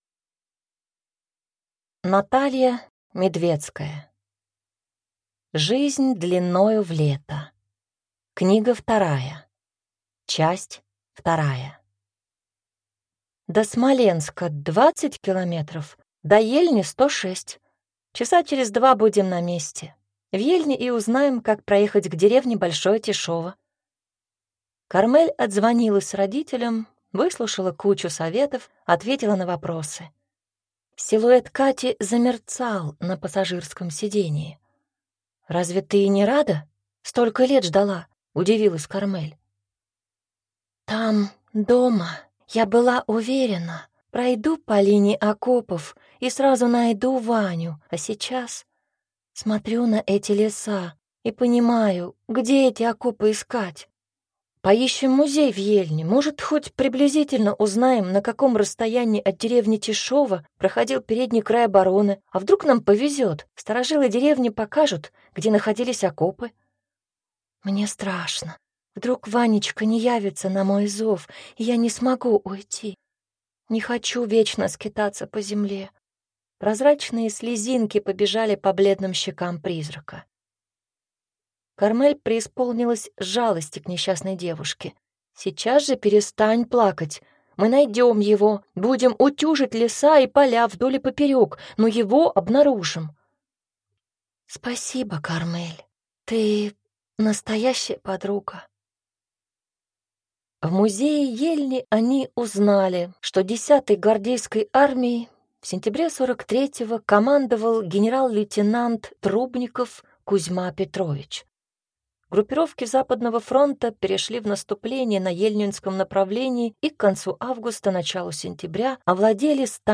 Аудиокнига Жизнь длиною в лето. Книга 2 | Библиотека аудиокниг